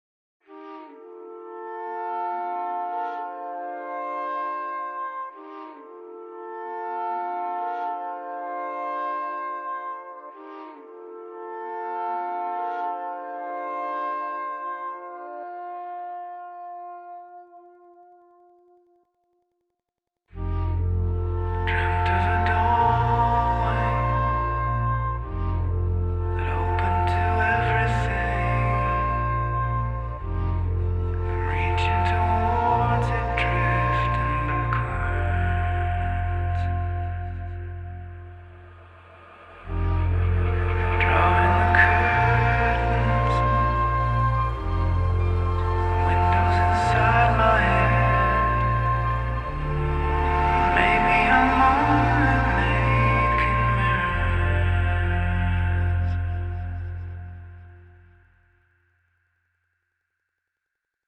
Genre: Indie Rock / Electronic / Alternative